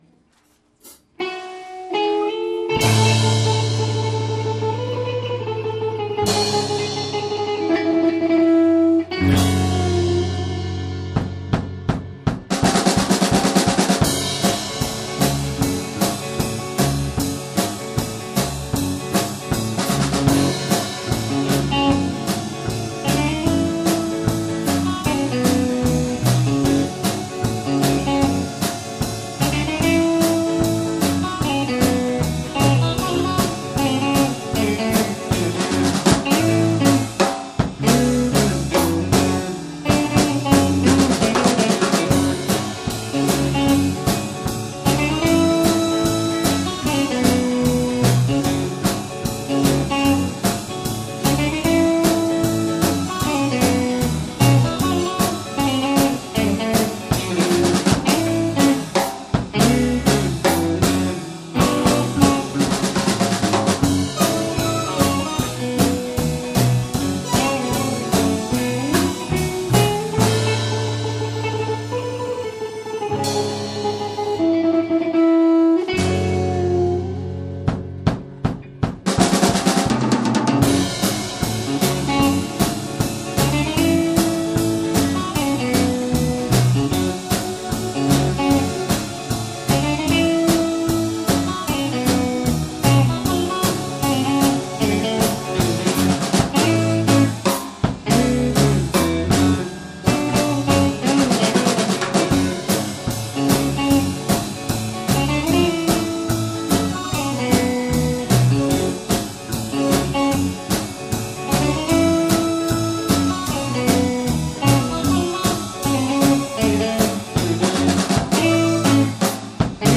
2017 Summer Live-1 | The Arou Can